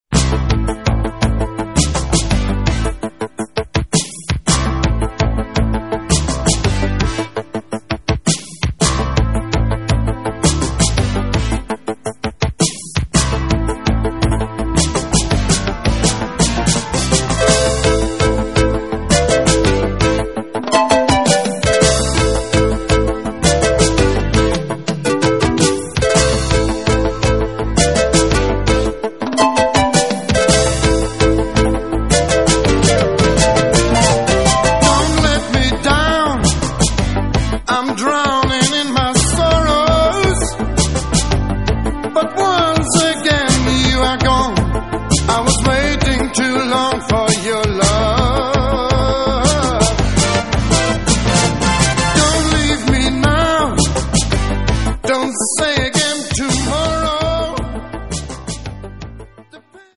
вокал, бас, перкуссия, клавишные